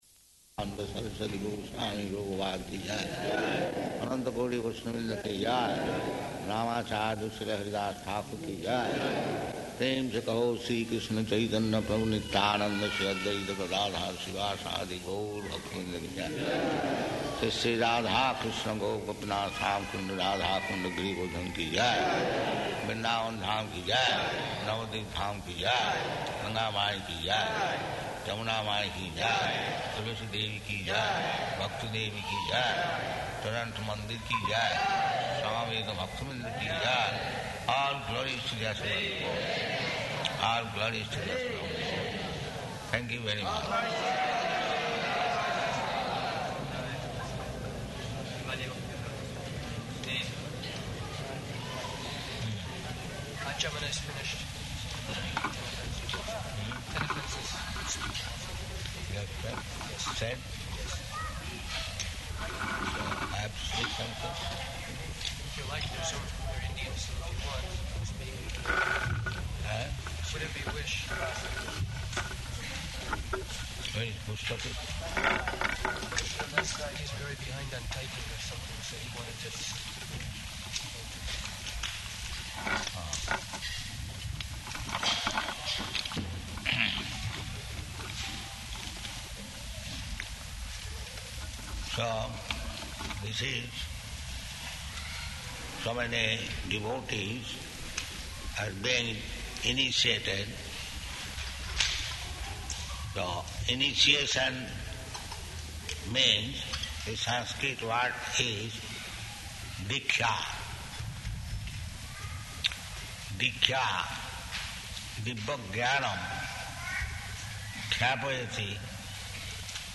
Initiation Lecture
June 18th 1976 Location: Toronto Audio file